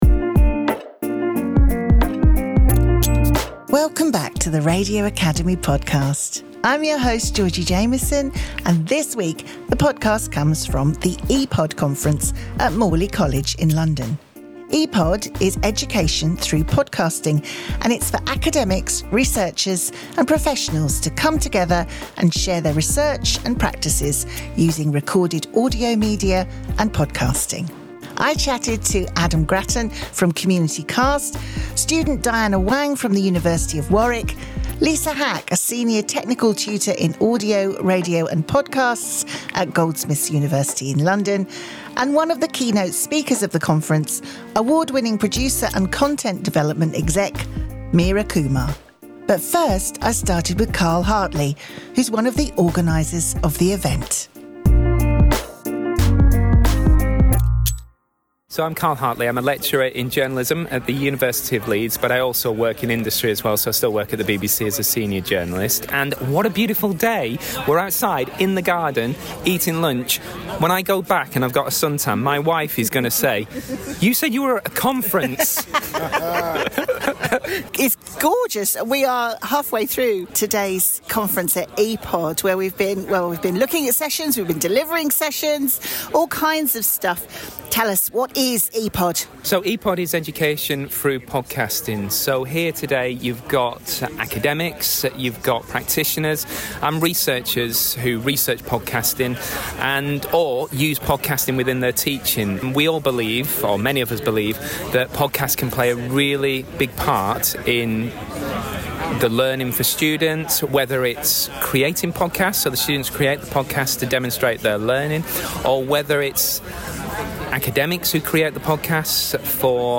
This week the podcast comes from the EPOD Conference at Morley College in London. EPOD, Education Through Podcasting, is for academics, researchers, and professionals to come together and share their research and practices using recorded audio media and podcasting.